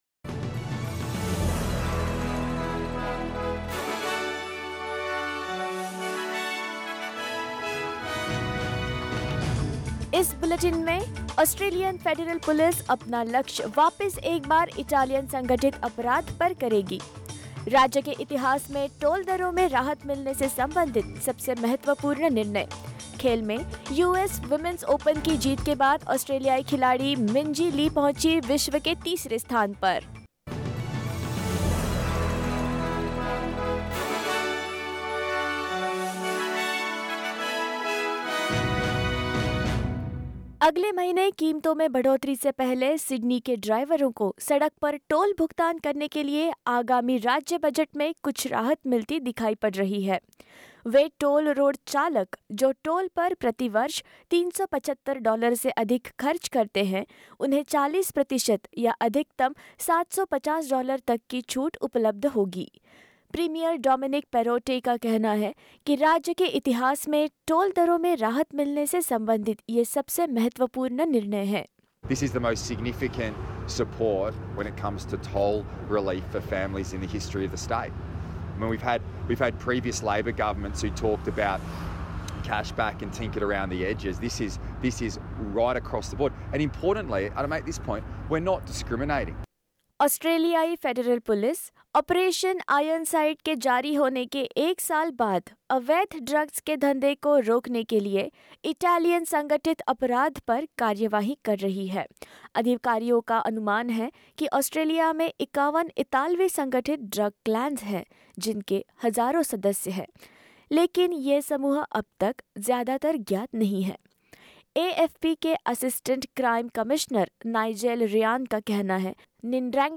In this latest SBS Hindi bulletin: NSW drivers could save up to $750 a year on tolls under new budget; New Zealand Prime Minister Jacinda Ardern to visit Sydney; Minjee Lee wins US Women's Open 2022 and more.